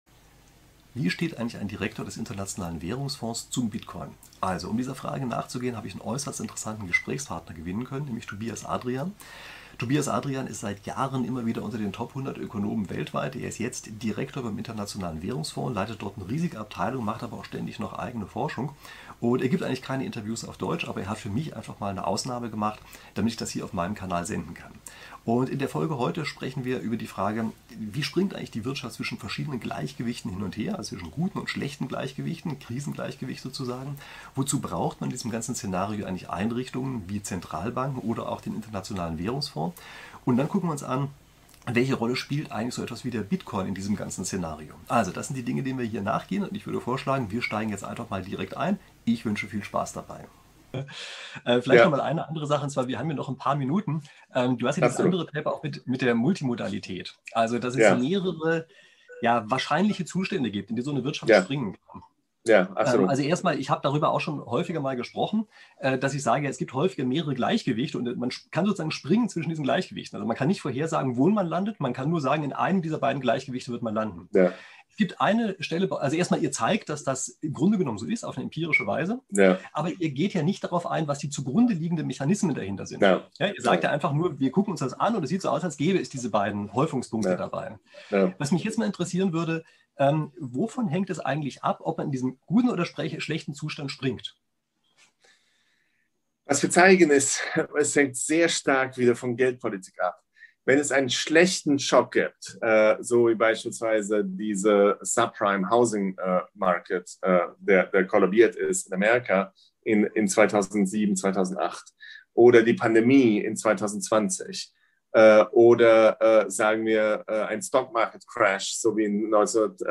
Tobias Adrian gehört seit Jahren zu den Top-100-Ökonomen weltweit und ist Direktor beim Internationalen Währungsfonds (IMF). Er nimmt hier Stellung zu seinem neuesten Paper über mehrere Gleichgewichte in der Wirtschaft und über die Rolle des Bitcoin.